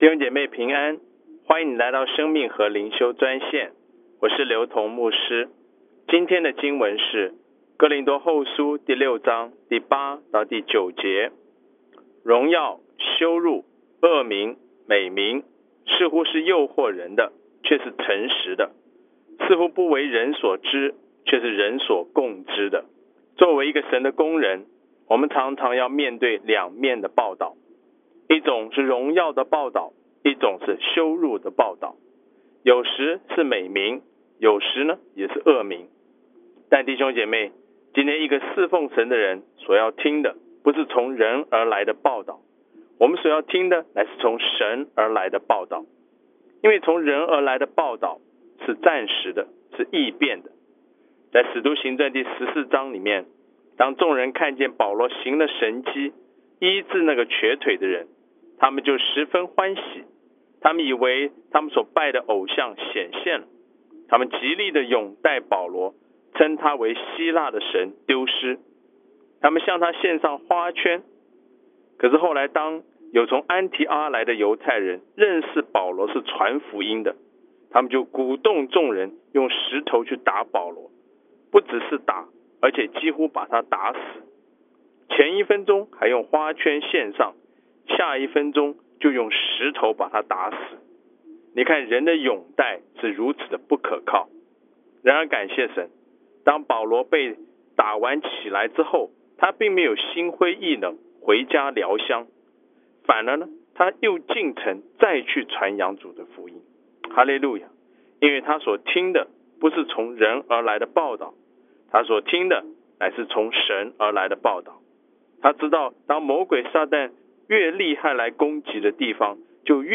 每日灵修专线地区每日灵修专线电话号码